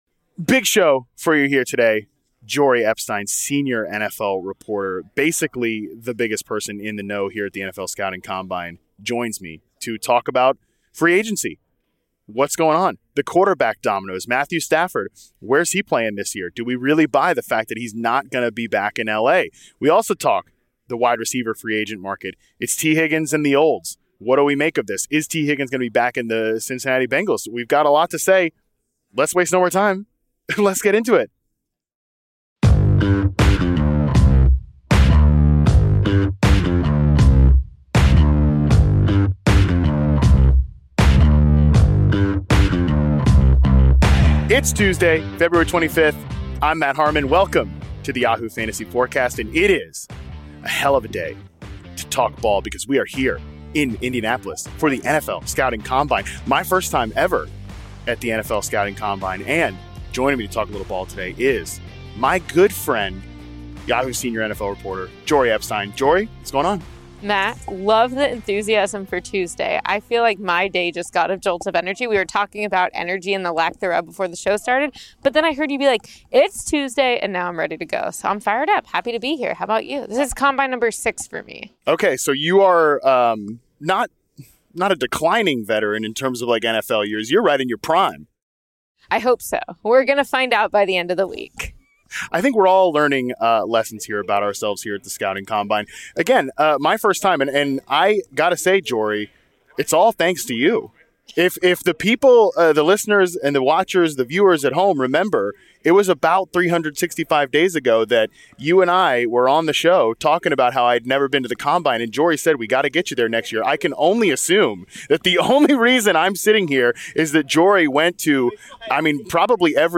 It's combine week on the pod as Yahoo Fantasy Forecast records from Radio Row in Indianapolis.